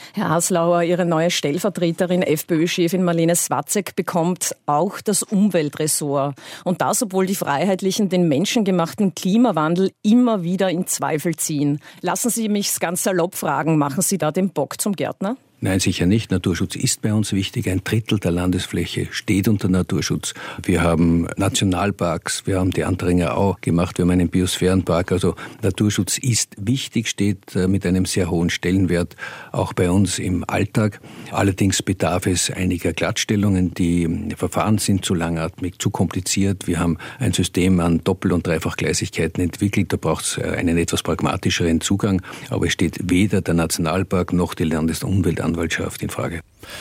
Heute morgens im Ö1 Morgenjournal: eine Journalistin fragt den Salzburger Landeshauptmann Haslauer, ob er